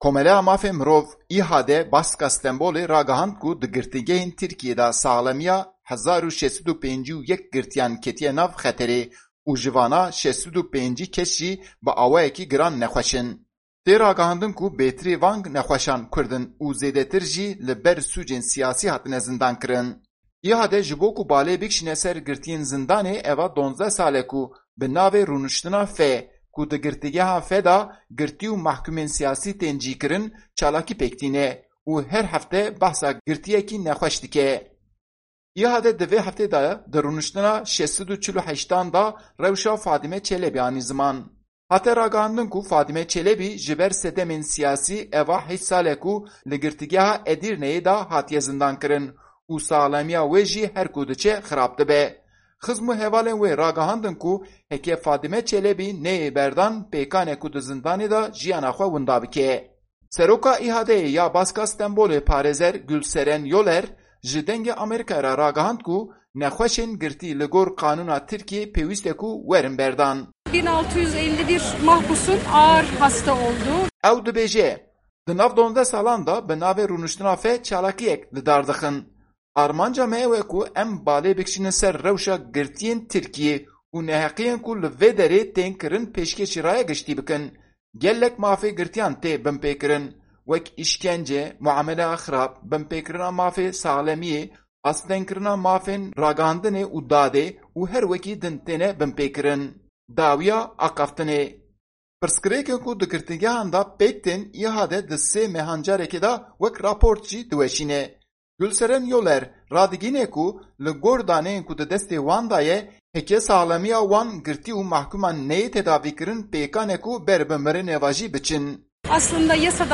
Raporta Deng